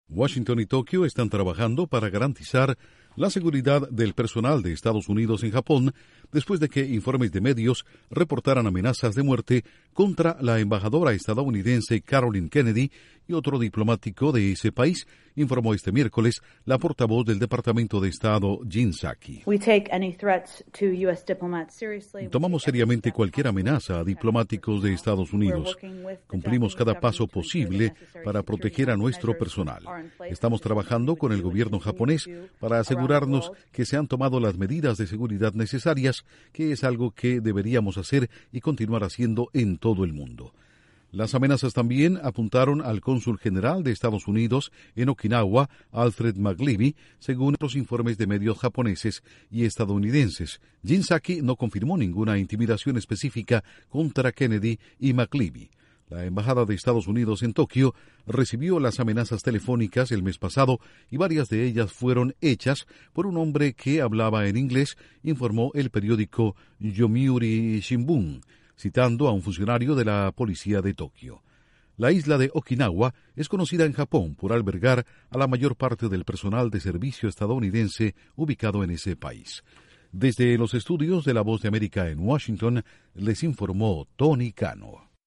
Estados Unidos y Japón trabajan en la seguridad de la embajada estadounidense en Tokio ante amenazas a sus funcionarios. Informa desde los estudios de la Voz de América en Washington